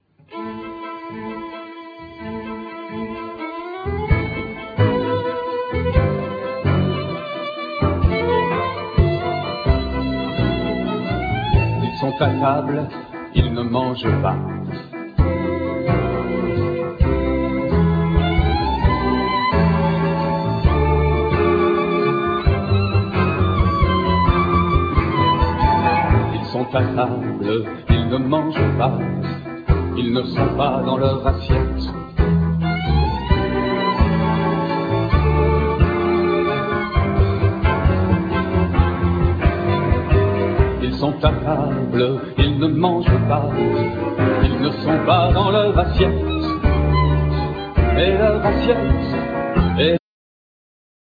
Live enregistre aux Francofolies de La Rochelle 2000
Vocals
Piano
Contrabass
Drums
1st Violin
Viola
Cello